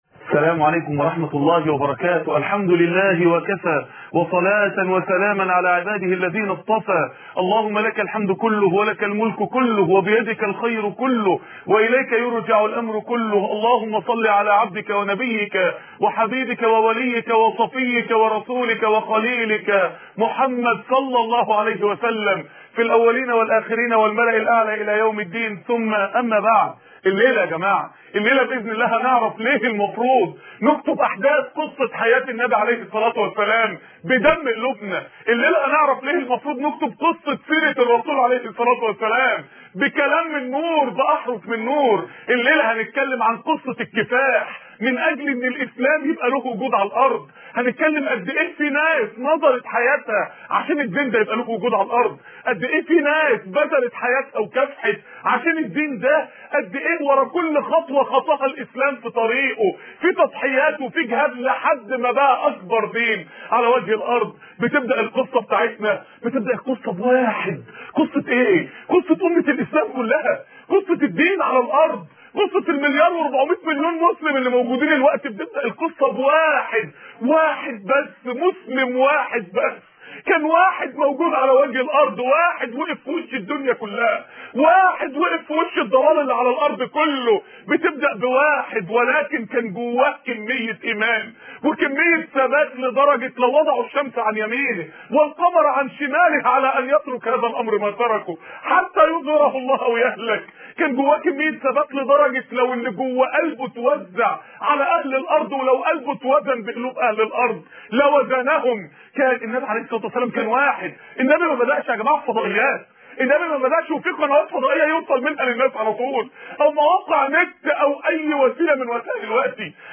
السنة المطهرة خطب ومحاضرات لماذا محمد ؟